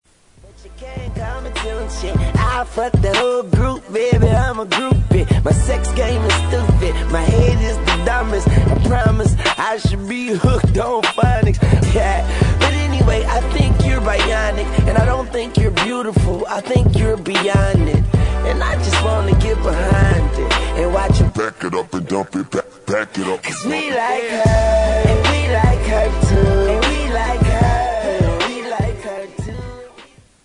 • Hip-Hop Ringtones